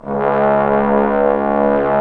Index of /90_sSampleCDs/Roland LCDP06 Brass Sections/BRS_Bs.Trombones/BRS_Bs.Bone Sect